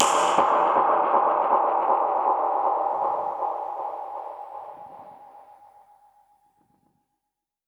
Index of /musicradar/dub-percussion-samples/125bpm
DPFX_PercHit_A_125-07.wav